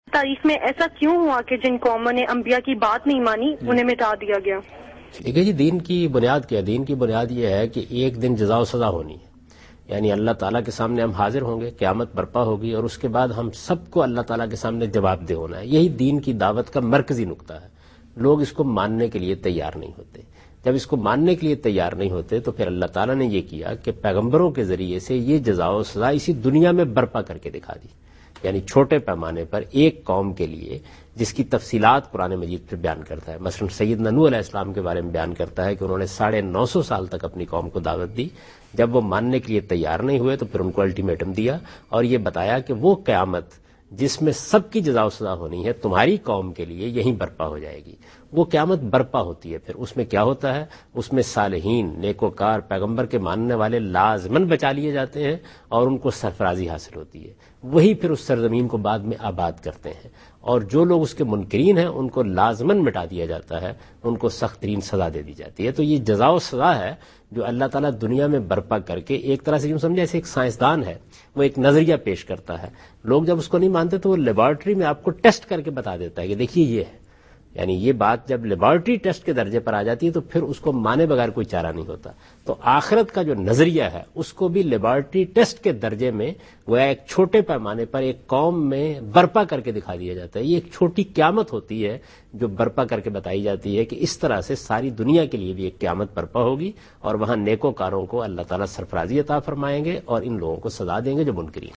Category: TV Programs / Dunya News / Deen-o-Daanish /
Javed Ahmad Ghamidi answers a question about "Reason of Torment upon Non-Believing People" in program Deen o Daanish on Dunya News.